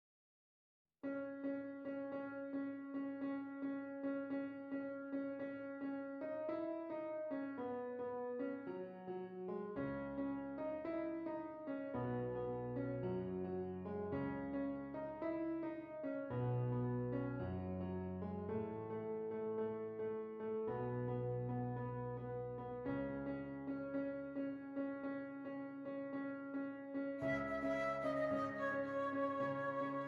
Flute Solo with Piano Accompaniment
Does Not Contain Lyrics
C Sharp Minor
Moderate Rock